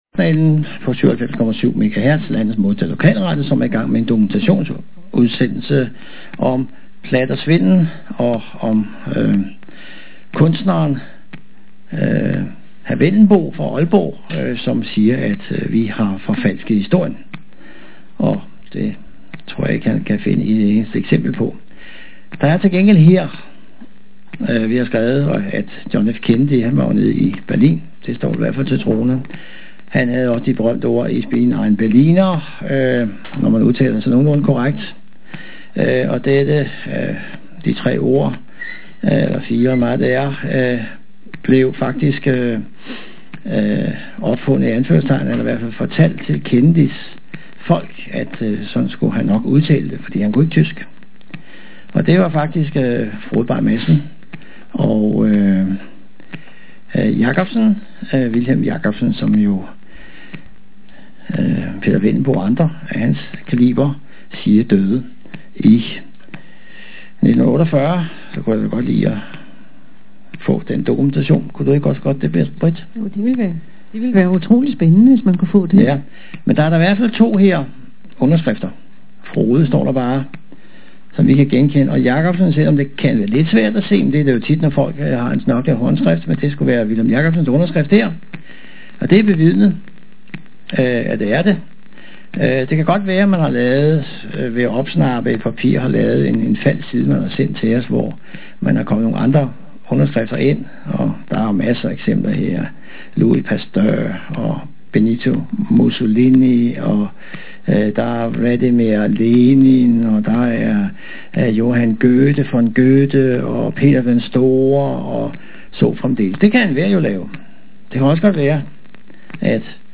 Optagelse fra Radio Frederiksberg-Journalen den 2. marts 2007 kl. 13.00. De havde stadig ikke fattet, at de var blevet afsl�ret.